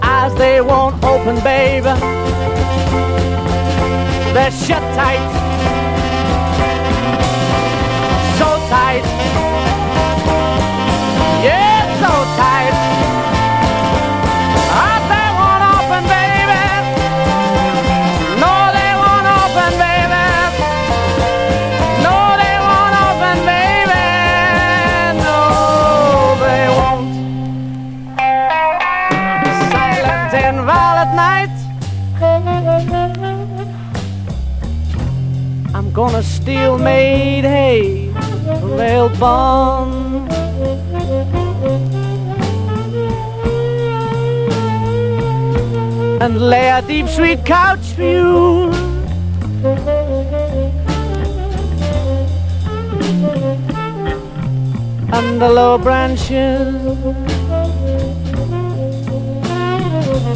PSYCHEDELIC ROCK / BRITISH FOLK / ACID FOLK / JAZZ ROCK